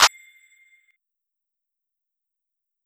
Clap (Space Cadet).wav